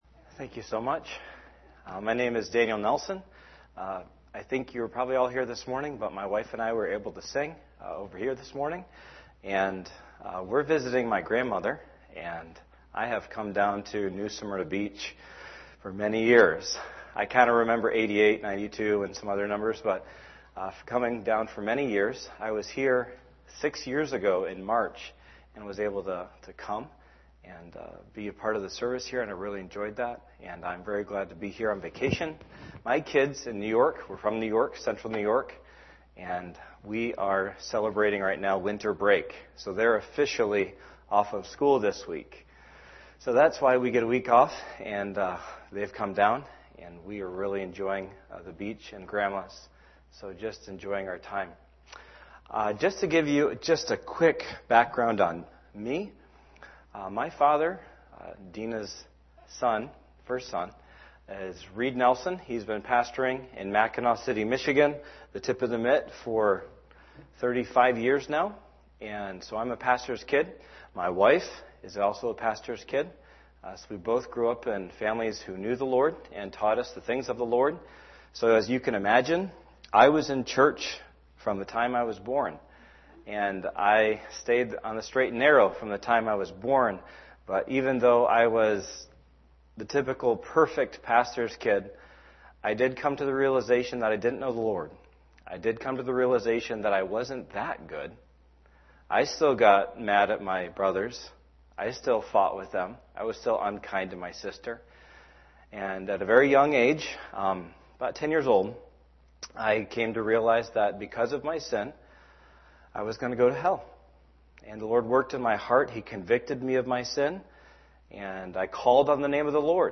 sermon2-16-20pm.mp3